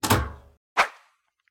safebox_out.mp3